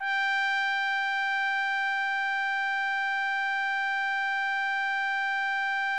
TRUMPET   26.wav